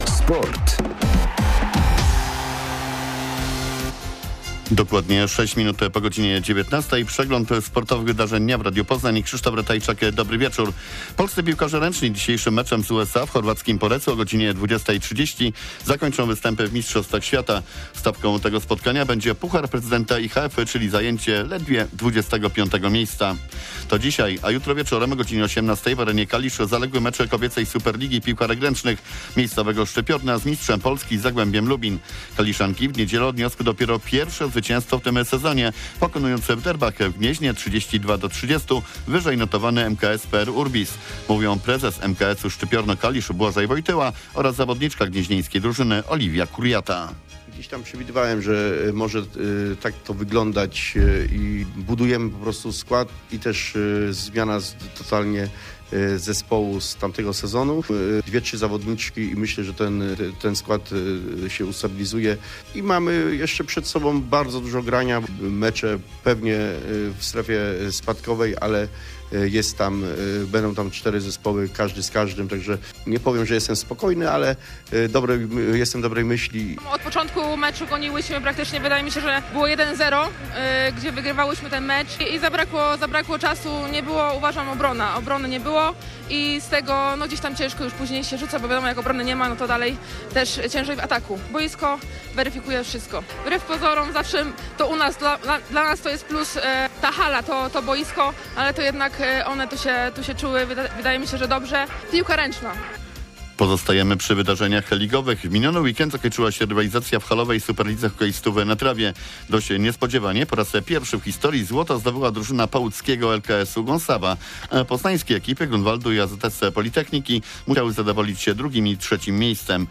28.01.2025 SERWIS SPORTOWY GODZ. 19:05